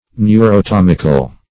Neurotomical \Neu`ro*tom"ic*al\